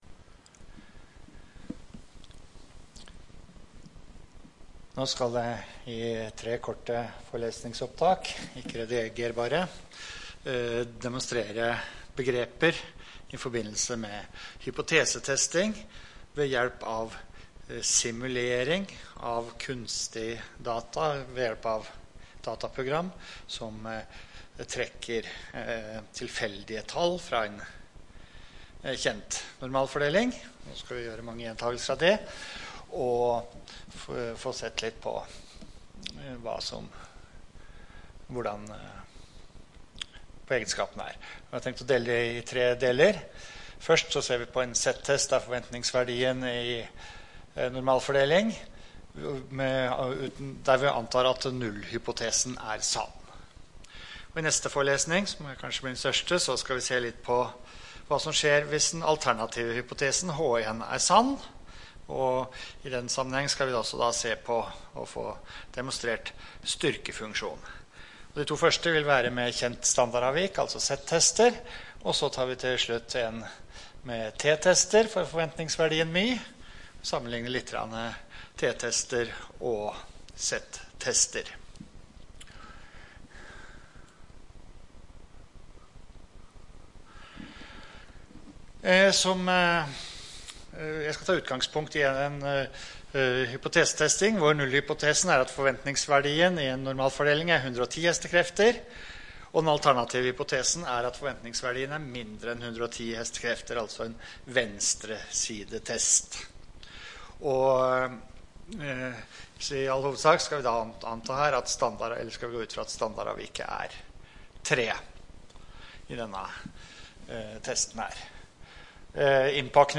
Rom: Lille Eureka, 1/3 Eureka